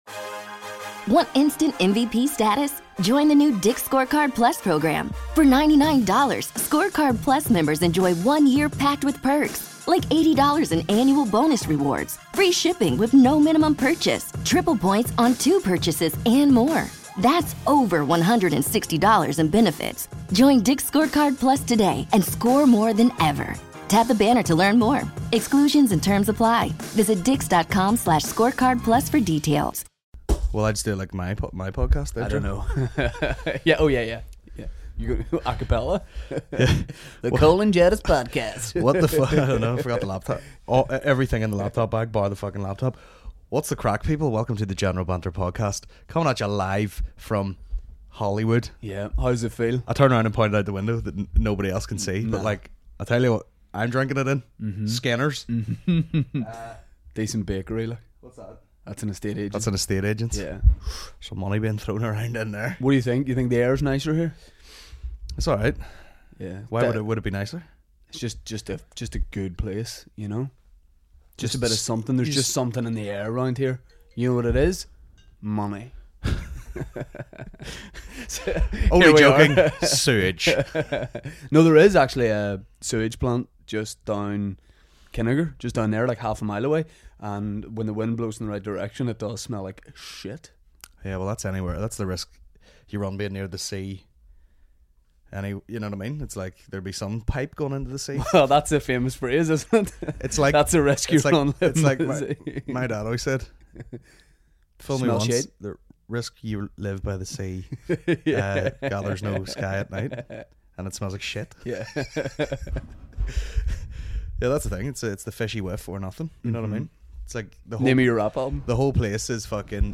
a Comedy podcast